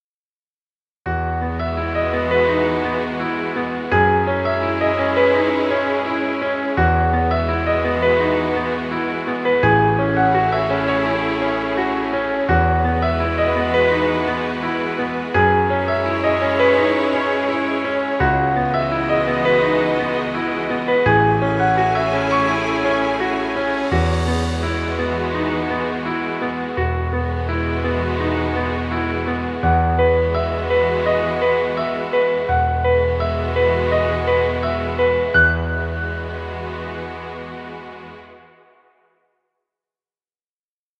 Emotional romantic music. Background music Royalty Free.